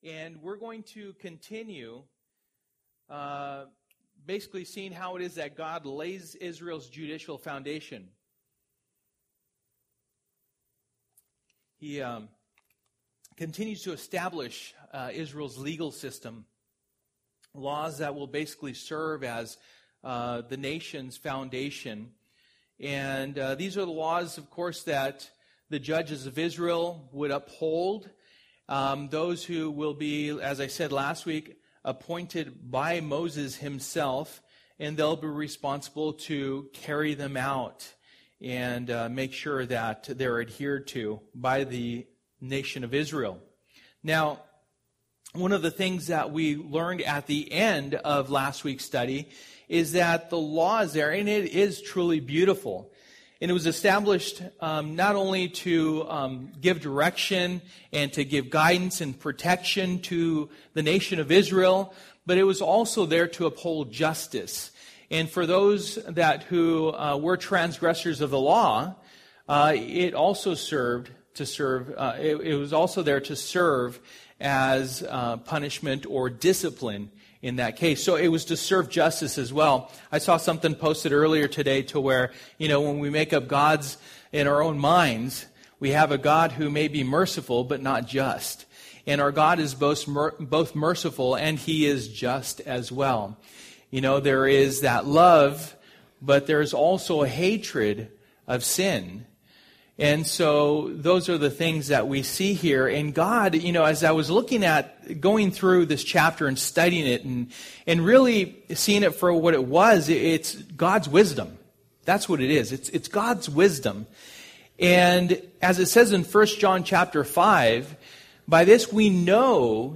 Passage: Exodus 22:1-31 Service: Wednesday Night